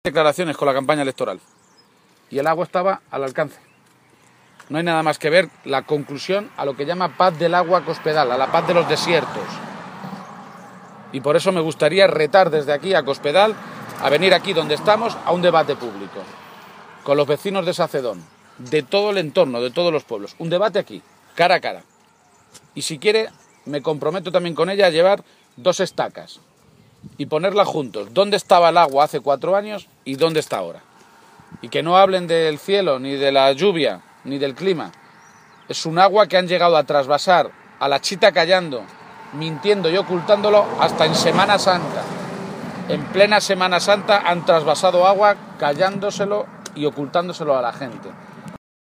García-Page se pronunciaba de esta manera esta mañana, en la localidad alcarreña de Sacedón, donde se ubica el pantano de Entrepeñas, uno de los dos grandes pantanos de cabecera del Tajo de los que sale el agua para el trasvase al Segura.